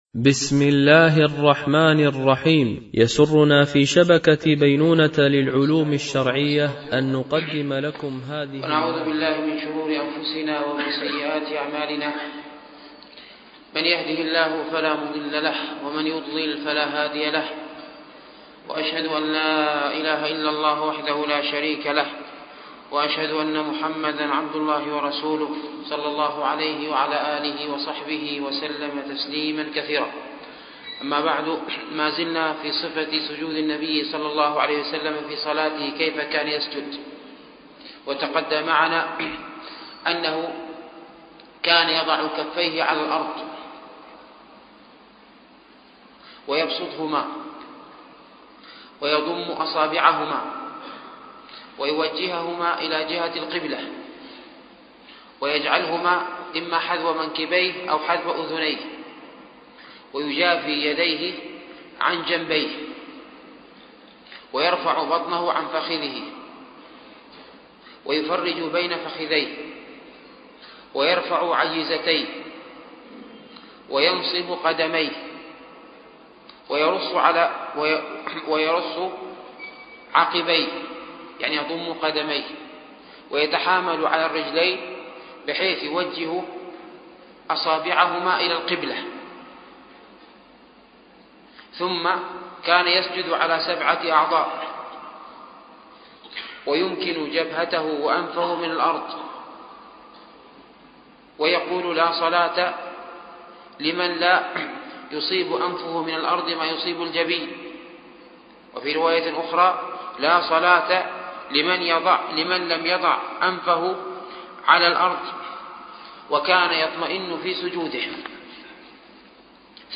فقه الصلاة ـ الدرس الرابع عشر